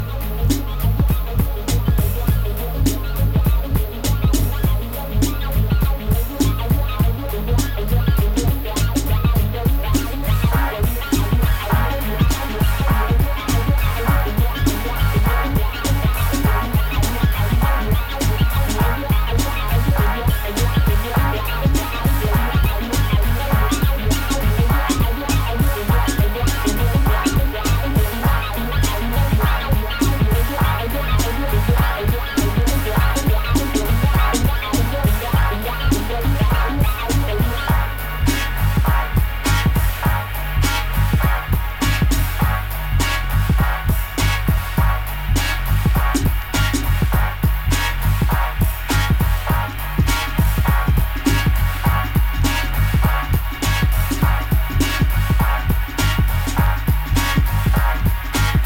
Side-AはロッキンBreak Beatで、Side-BはBPM100程度のDubby Breaks。